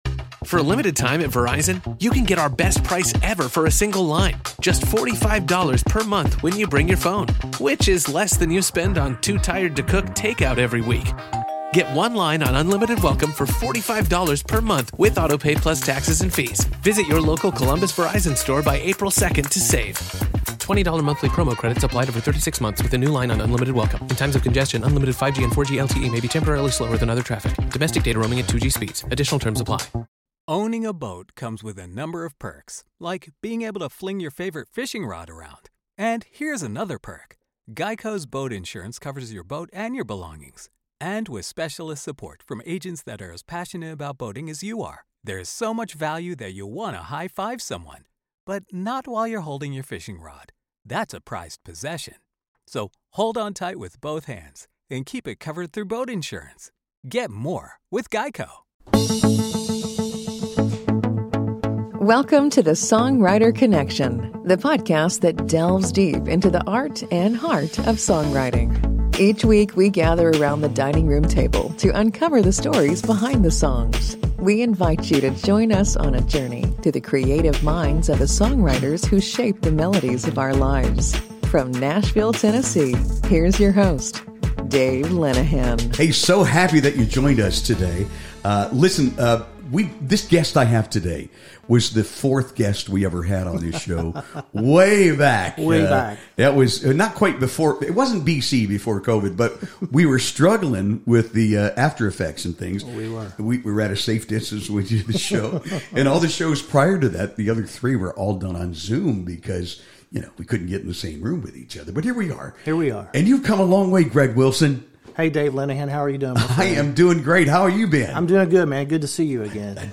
Tune in for an intimate conversation and performances that showcase the power of music to connect us all.